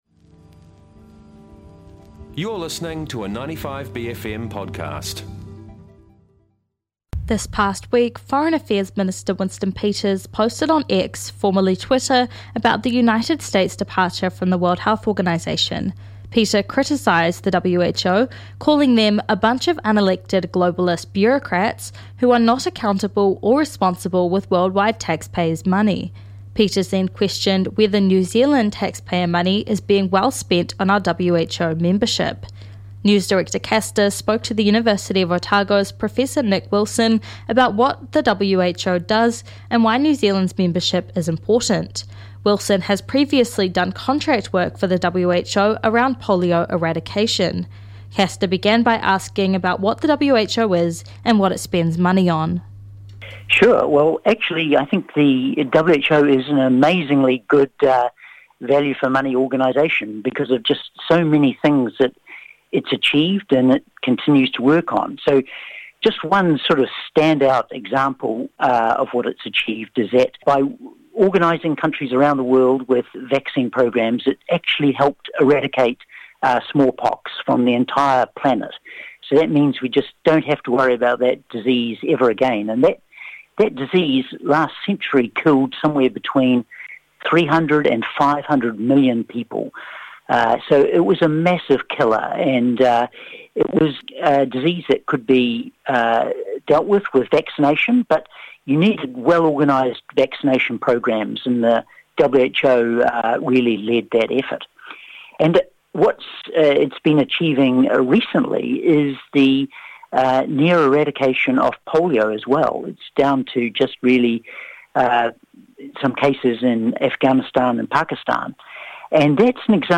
News & Current Affairs show